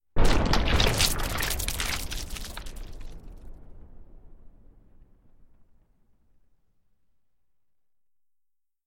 Звук разлетающихся кишок после взрыва